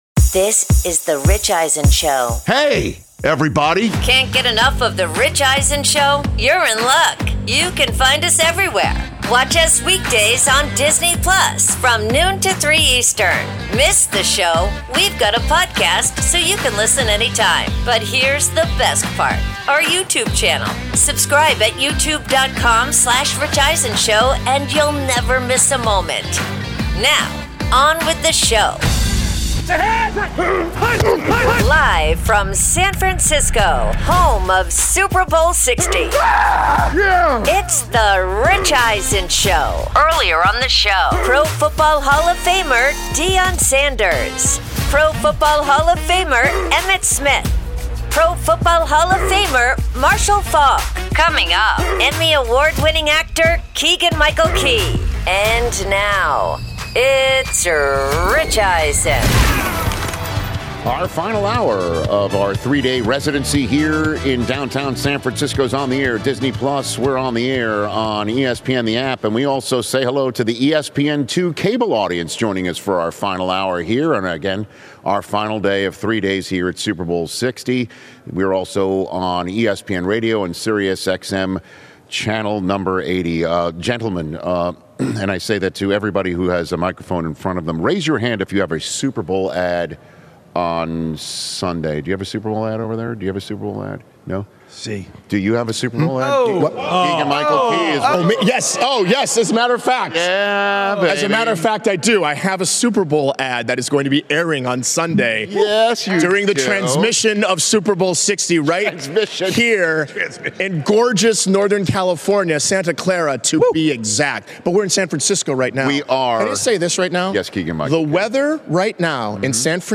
Hour 3: Live from Super Bowl LX with Keegan-Michael Key, plus ‘What’s More Likely’ Podcast with Rich Eisen